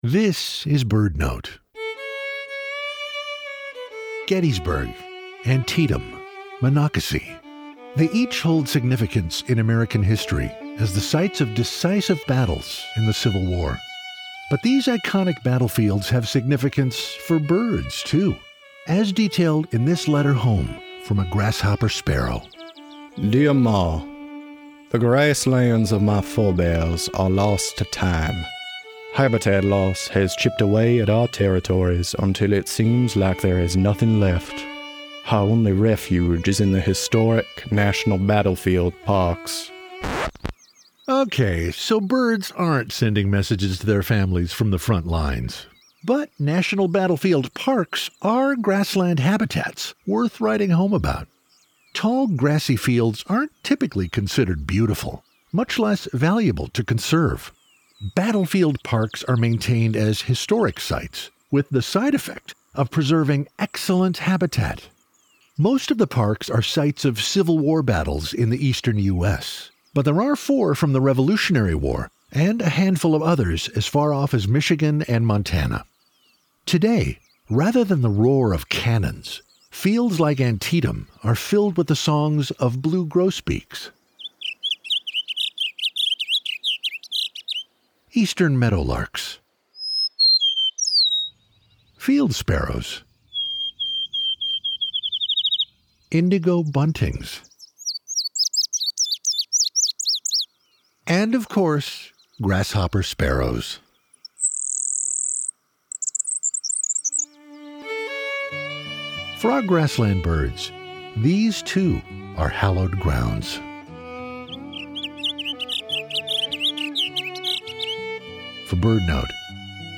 But battlefield monuments and military parks are maintained as historic sites, with the side-effect of preserving excellent habitat. Today, rather than the roar of cannons, battlefields like Gettysburg, Antietam, and Monocacy are filled with the sounds of grassland songbirds.